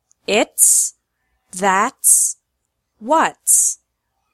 ‘s is pronounced like S in these words: